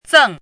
拼音： zèng
注音： ㄗㄥˋ